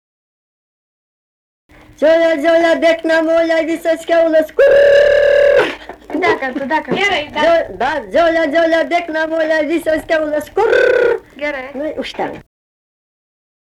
Dalykas, tema daina
Erdvinė aprėptis Mantvydai
Atlikimo pubūdis vokalinis